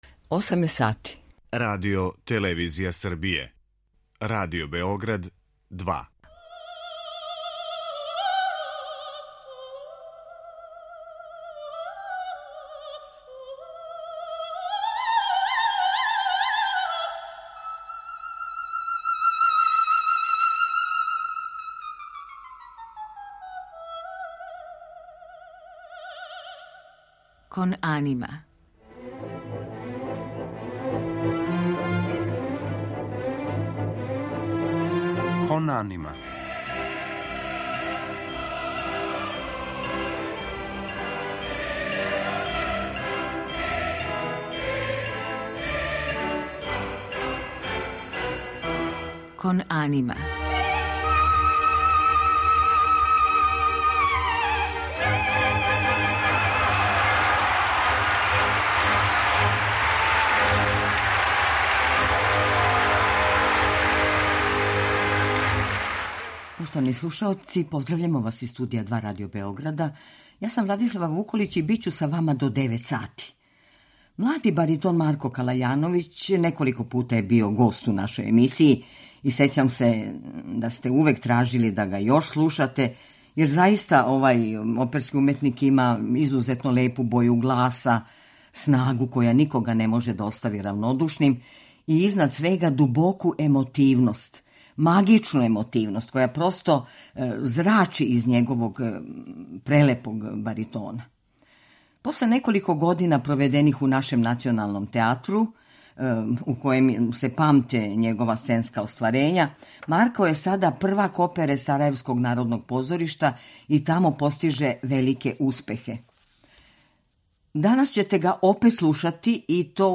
То се и очекивало, јер овај млади уметник поседује изузетно лепу боју гласа, снагу и изражајност које никога не могу да оставе равнодушним и, изнад свега, дубоку, магичну емотивност, која зрачи из његовог прелепог баритона.
Он је гост у емисији и слушаоци могу да га чују у најлепшим аријама, баритонског фаха ‒ из опера Чајковског, Доницетија, Бородина и Вердија.